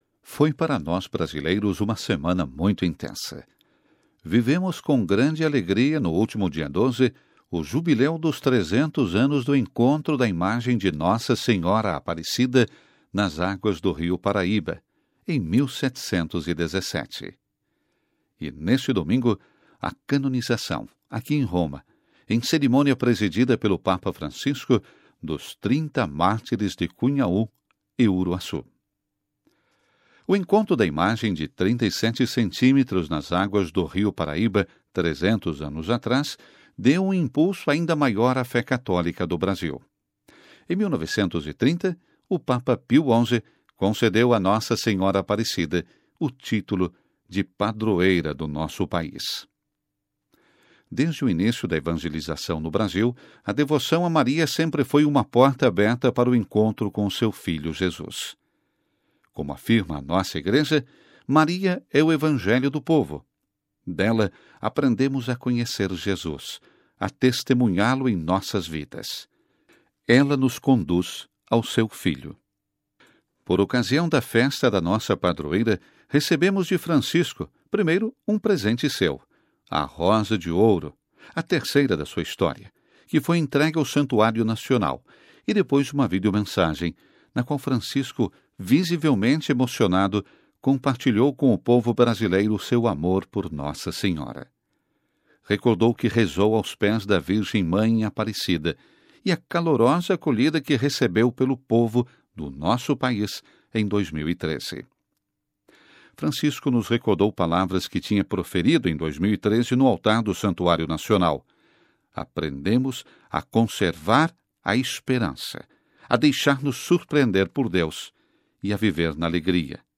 Editorial: Gotas de santidade